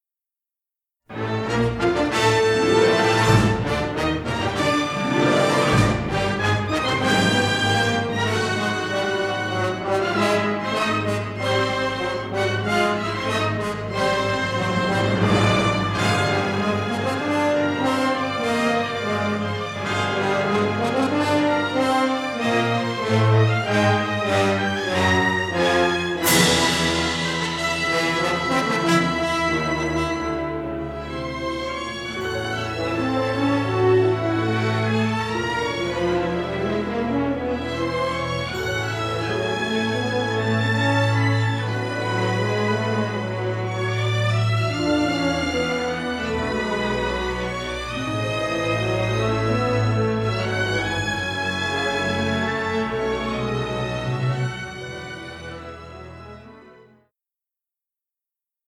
rich symphonic score
three-channel stereo scoring session masters